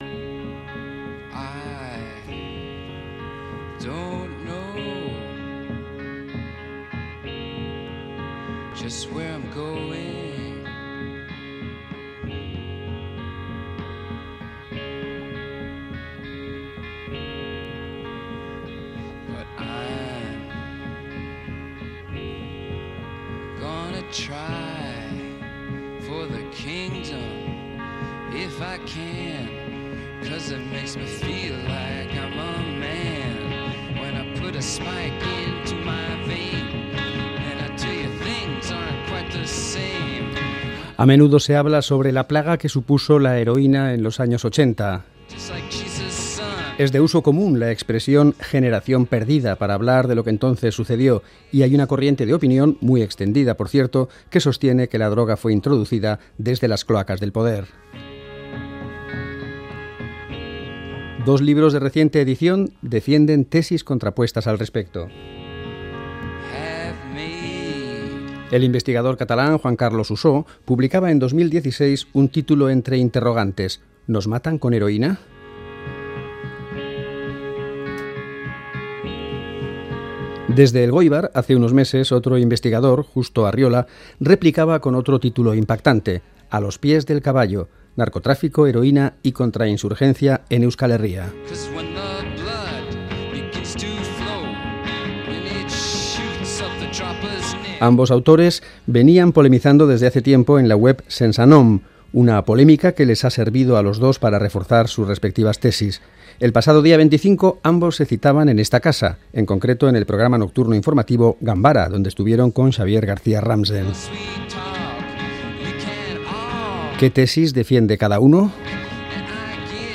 Nosostros extraemos los momentos clave de aquel encuentro.